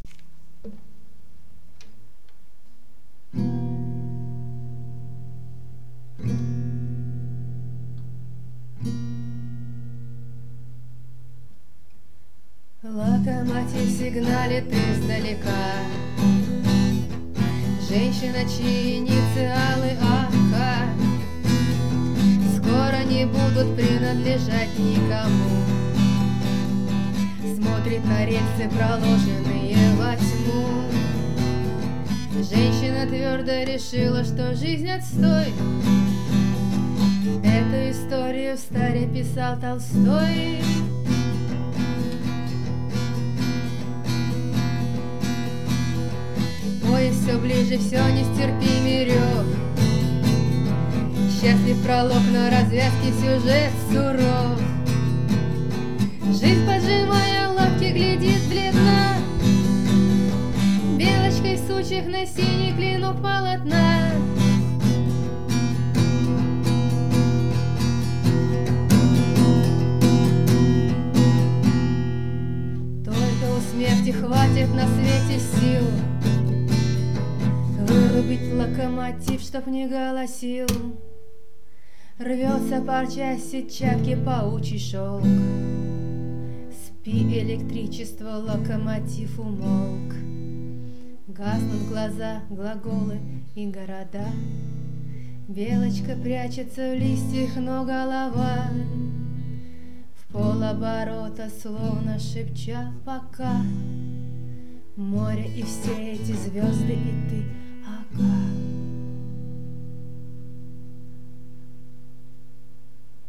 записано o3.04.2012 в Бергене, Норвегия